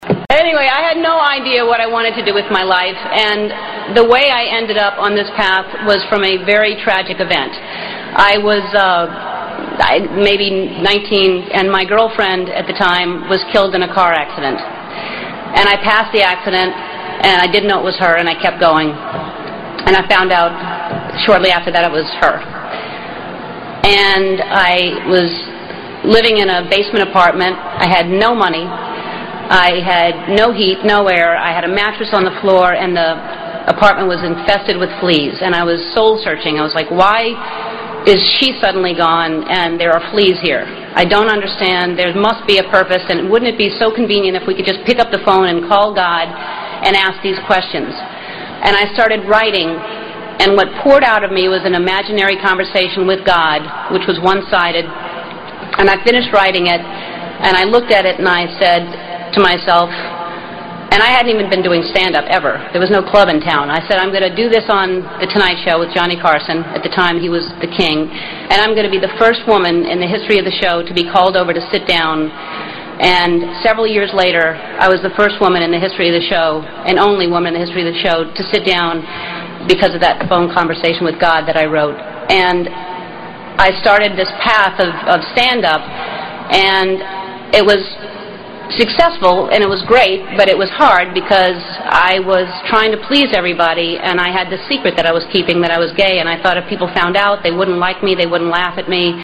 在线英语听力室偶像励志英语演讲 第81期:做真正的自己(3)的听力文件下载,《偶像励志演讲》收录了娱乐圈明星们的励志演讲。